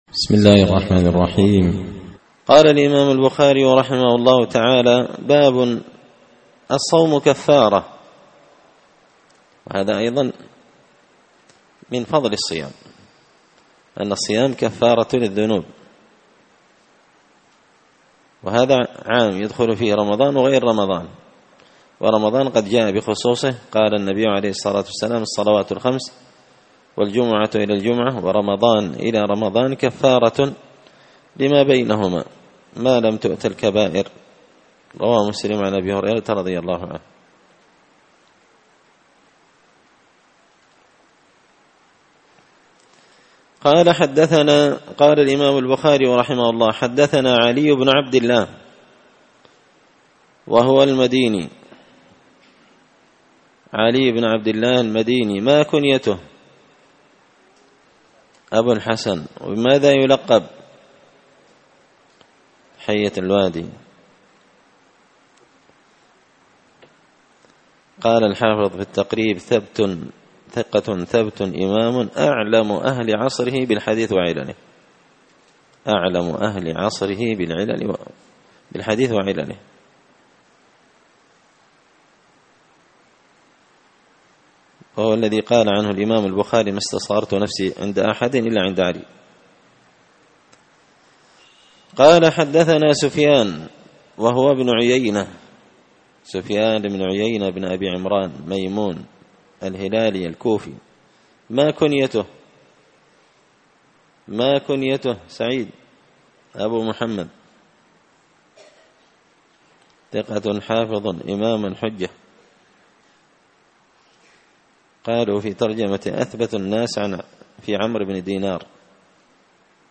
كتاب الصيام من صحيح البخاري الدرس الثالث (3) باب الصوم كفارة